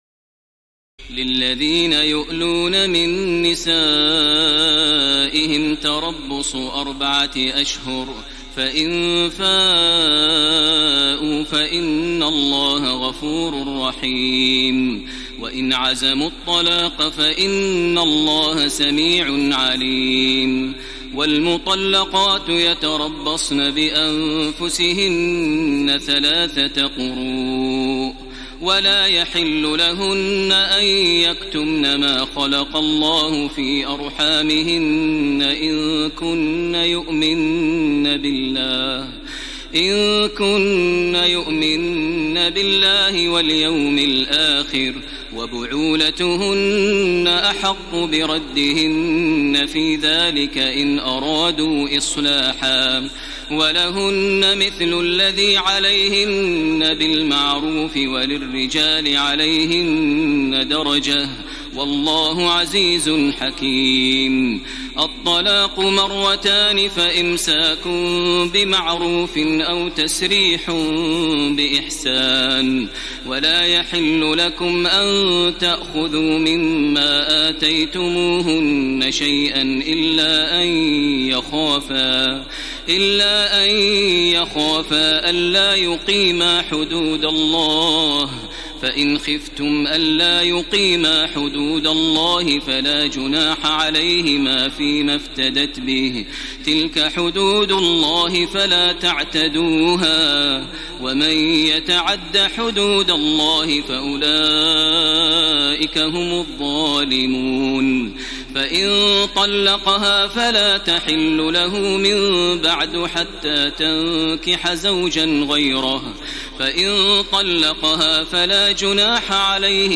تراويح الليلة الثانية رمضان 1433هـ من سورة البقرة (226-271) Taraweeh 2 st night Ramadan 1433H from Surah Al-Baqara > تراويح الحرم المكي عام 1433 🕋 > التراويح - تلاوات الحرمين